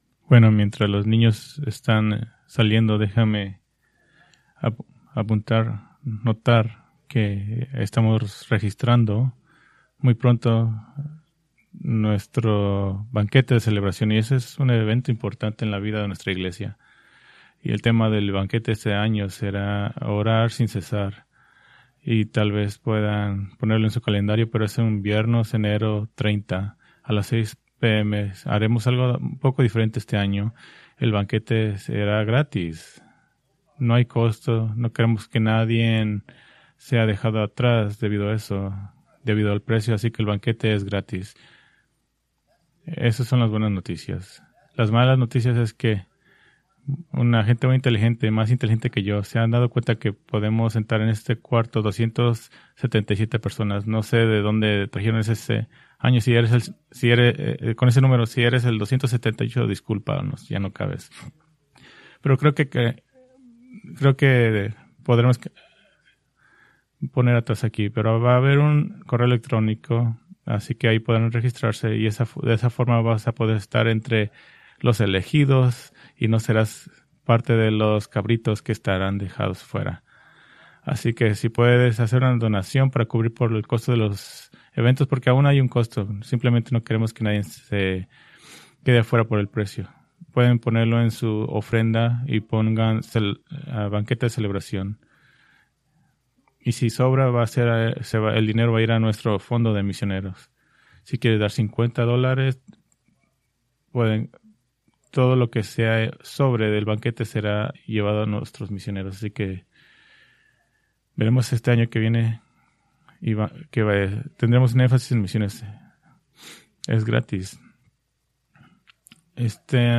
Preached December 14, 2025 from Lucas 1:50-53